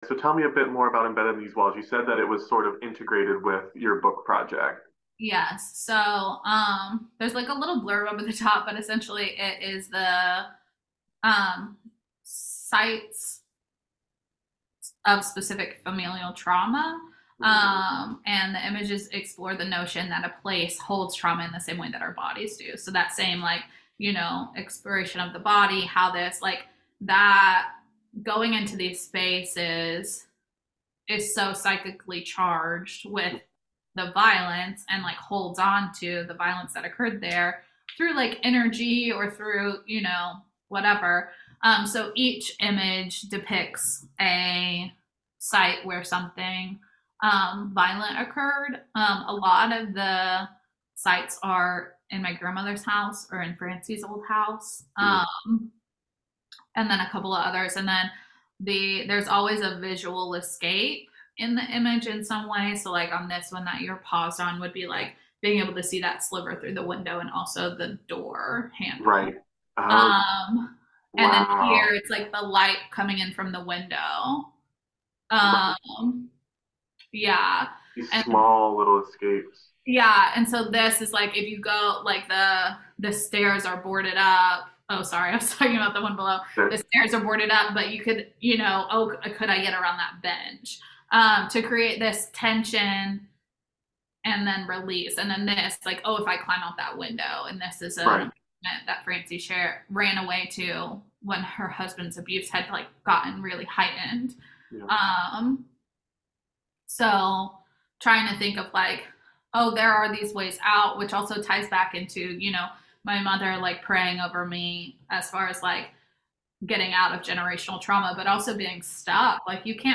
Interview
over Zoom